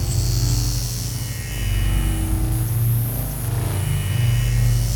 gauss_siege_loop.wav